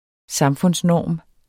Udtale [ ˈsɑmfɔns- ]